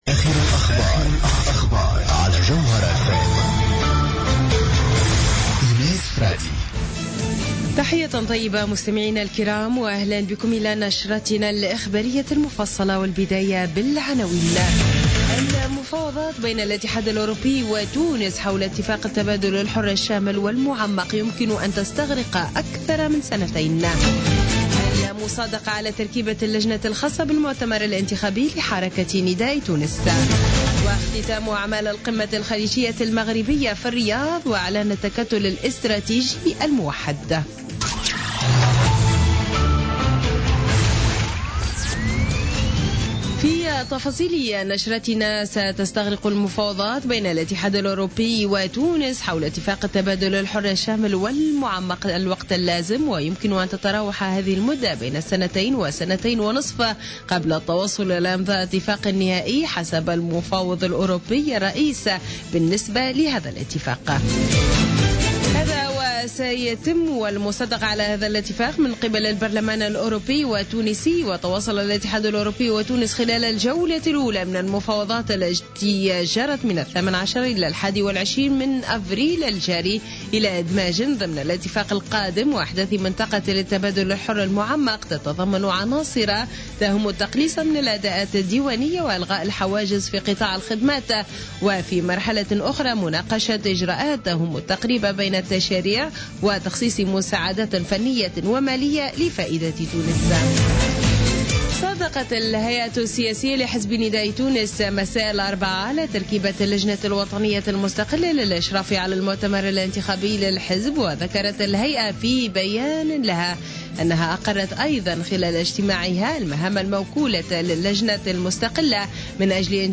نشرة أخبار منتصف الليل ليوم الخميس 21 أفريل 2016